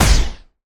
poly_shoot_energynuke.wav